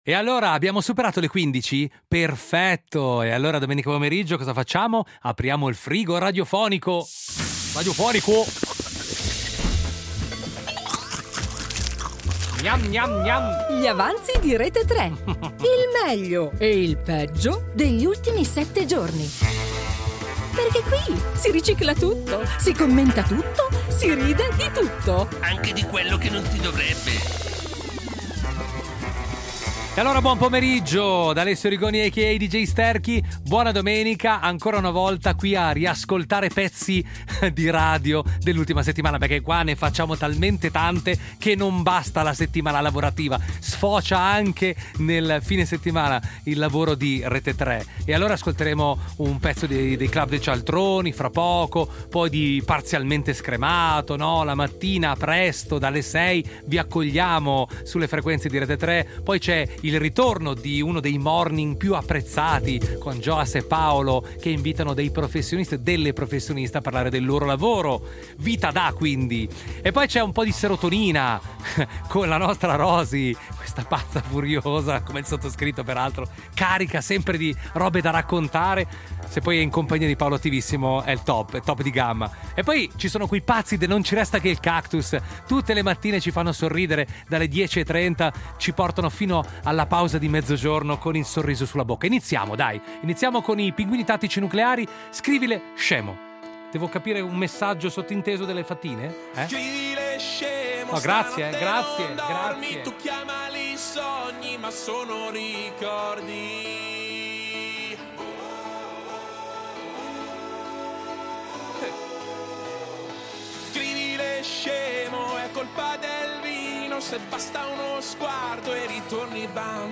Due ore di musica, momenti memorabili (o dimenticabili), notizie che hanno fatto rumore e altre che hanno solo fatto vibrare il telefono. Un programma che non butta via niente: si ricicla tutto, si commenta tutto, si ride di tutto.